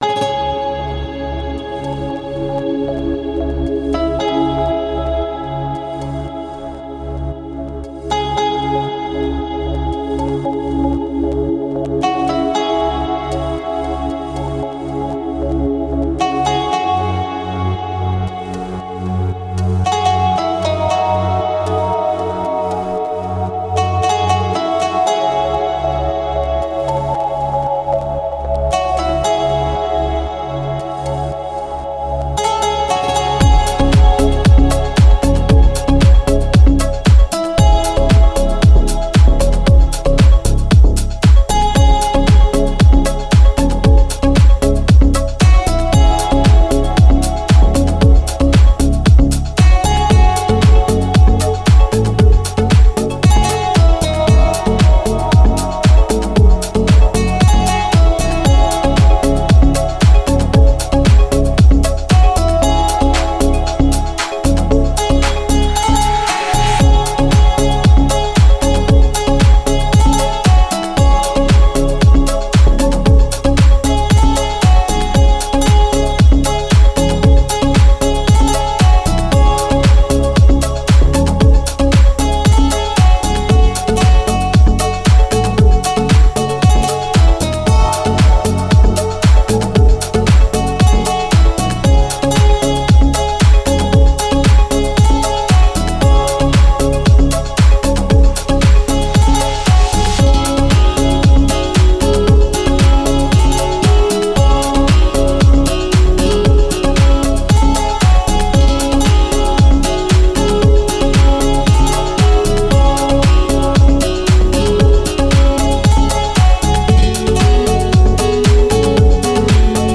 красивая музыка без слов